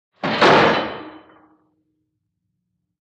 PrisonCellDoorSlam PE802002
DOORS VARIOUS PRISON DOORS: Cell door slam, fast.